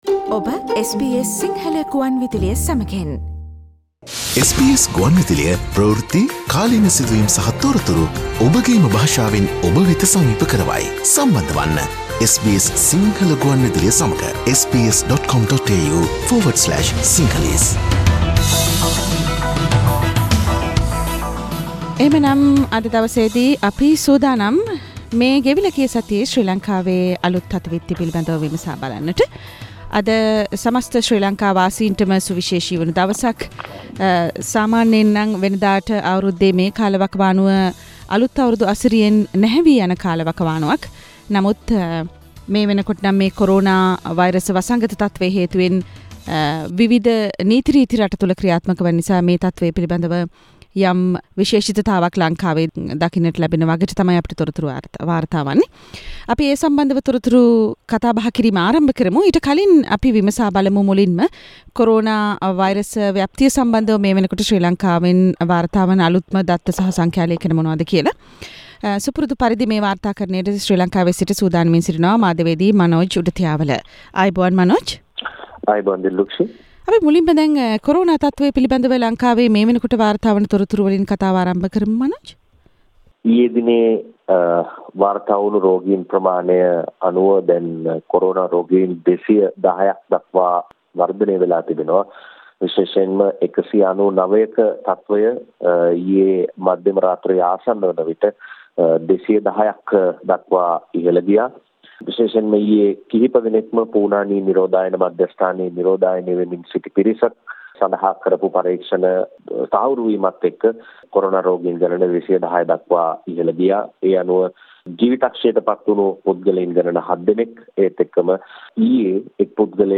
weekly Sri Lankan news wrap Source: SBS Sinhala radio